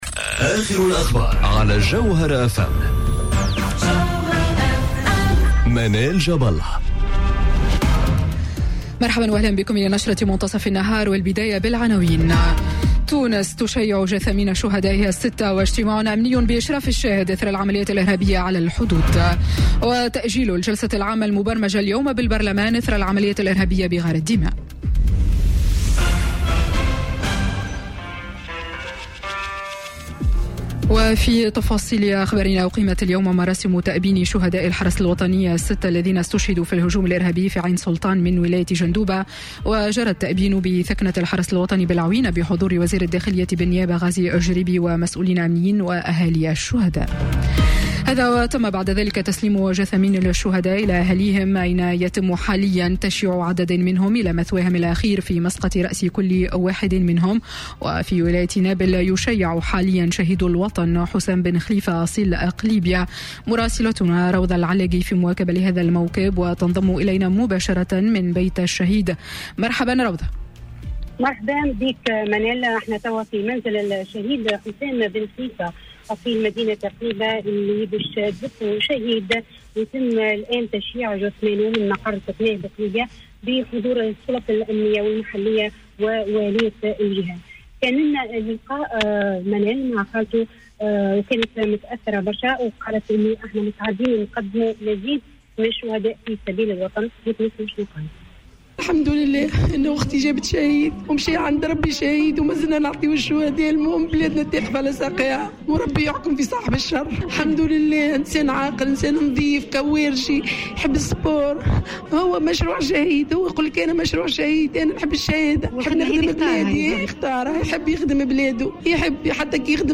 نشرة أخبار منتصف النهار ليوم الاثنين 9 جويلية 2018